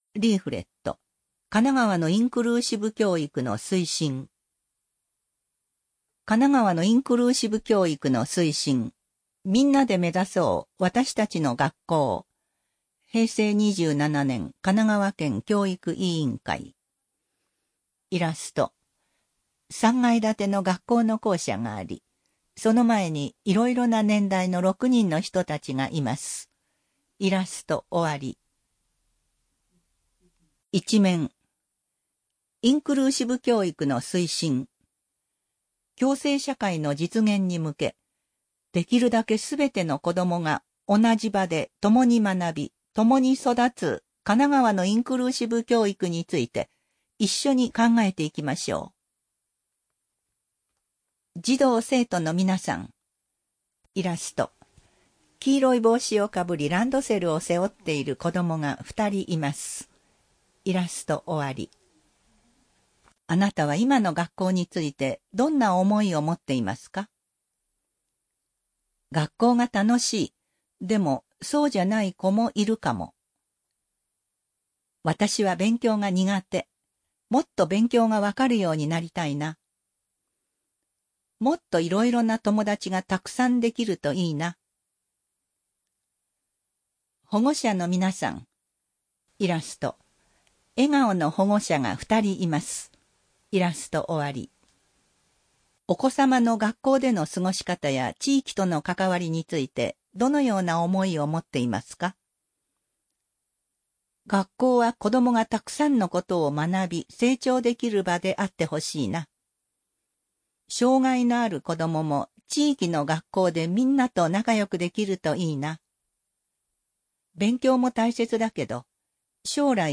点字版 白黒反転版 リーフレット「かながわのインクルーシブ教育の推進」音声版 音声版は、リーフレットの内容やイラストも含めて、音訳して作成しました。